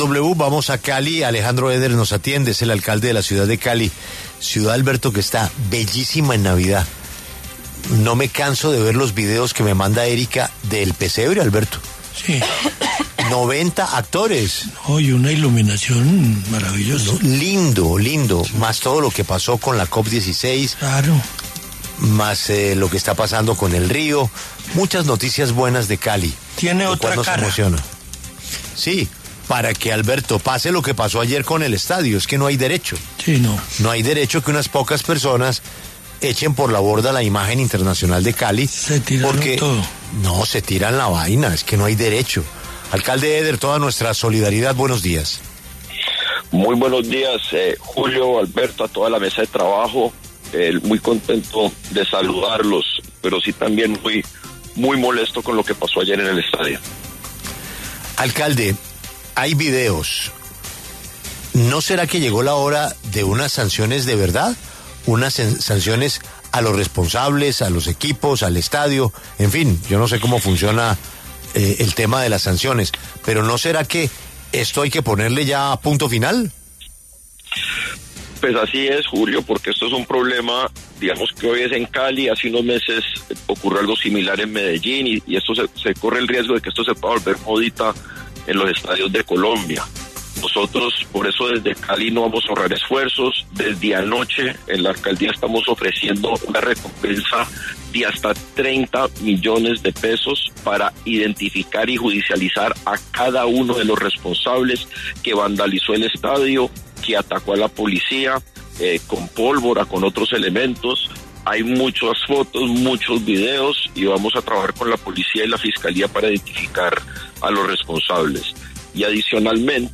El alcalde de Cali, Alejandro Éder, habló en La W sobre los disturbios que se presentaron este domingo en el marco de la final de la Copa Colombia disputada entre Nacional y América de Cali.